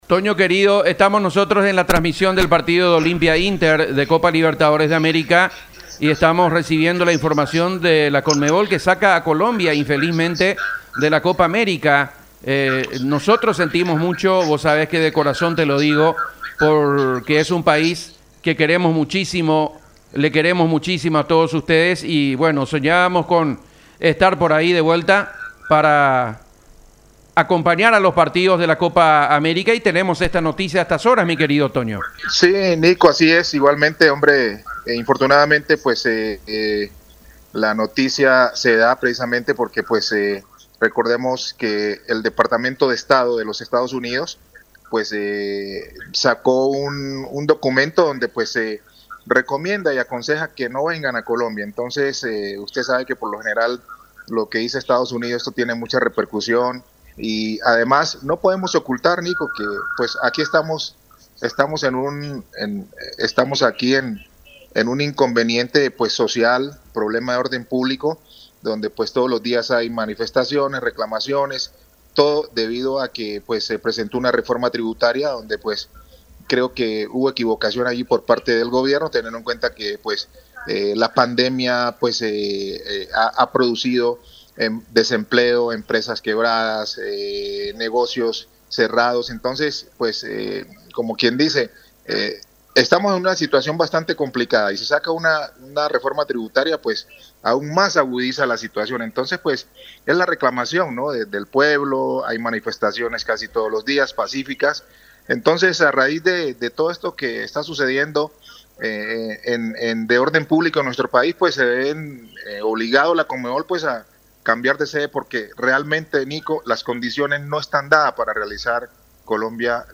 habló con Unión FC de cómo toman la decisión en Colombia.